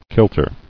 [kil·ter]